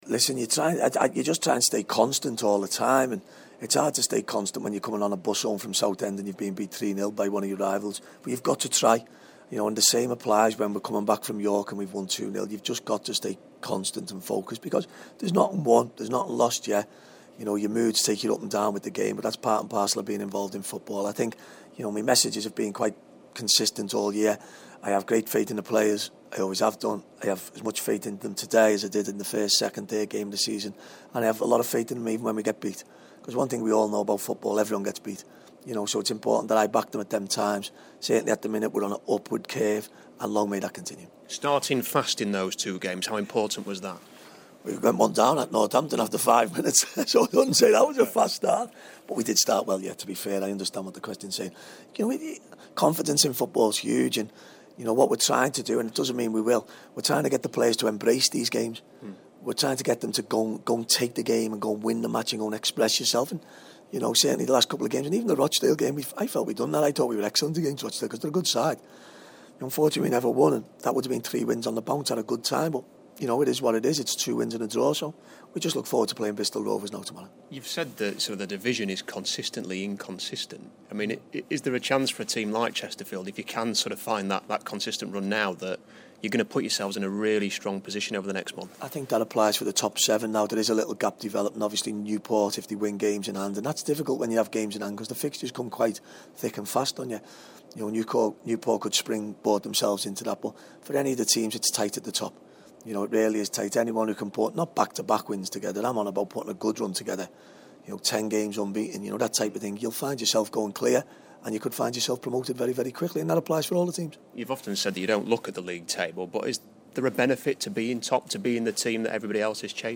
Interview: Chesterfield boss Paul Cook pre-Bristol Rovers & on deadline day